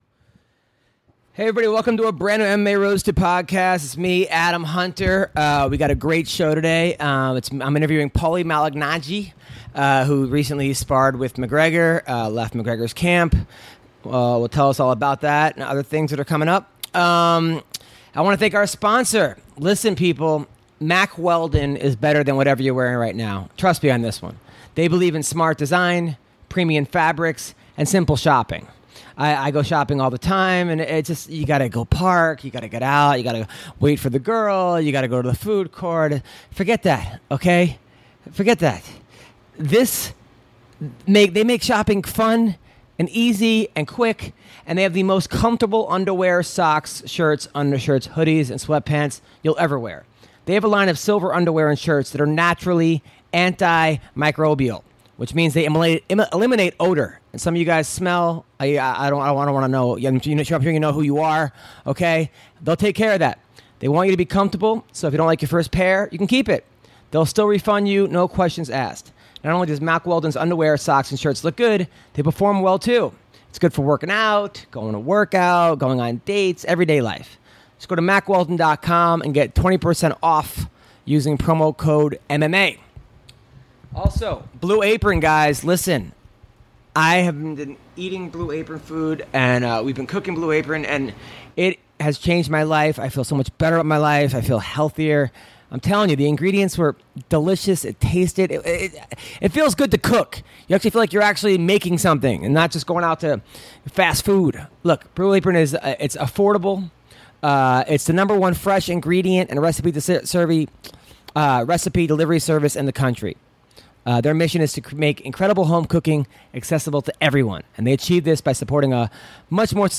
Paulie Malignaggi calls in to discuss Conor McGreggor Vs. Mayweather.